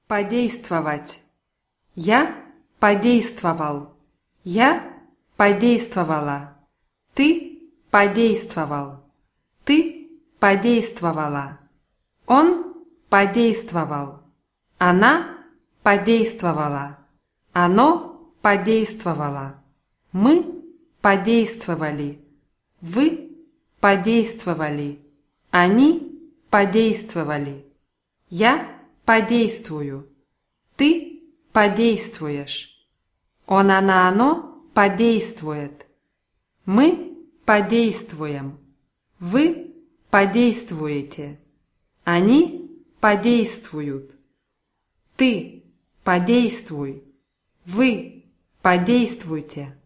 подействовать [padéjstvavatʲ]